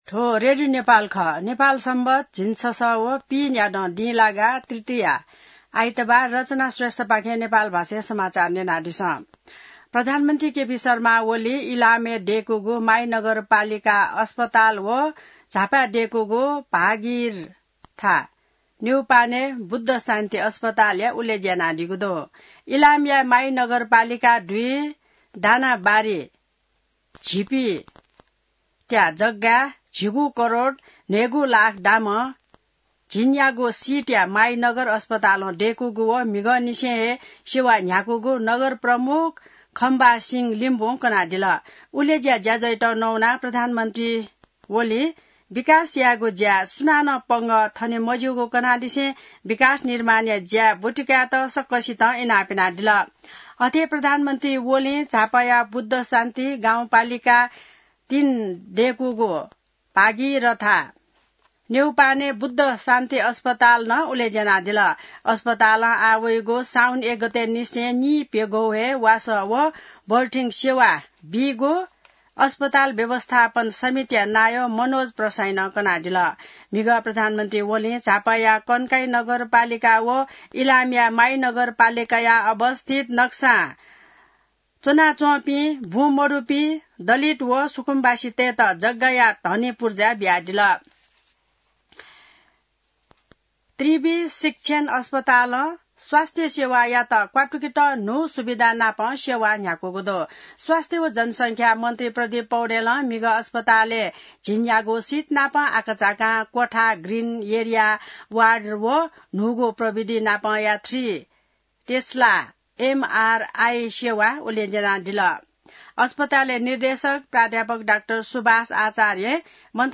नेपाल भाषामा समाचार : २९ असार , २०८२